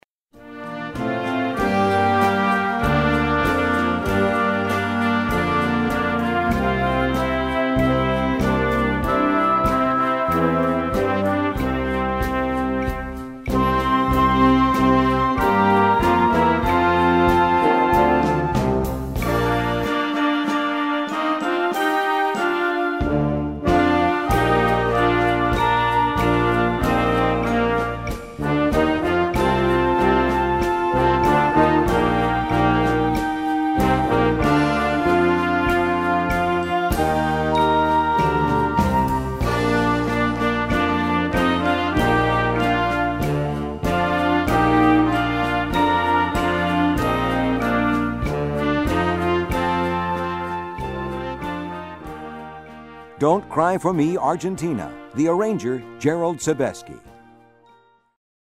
1 Besetzung: Blasorchester Tonprobe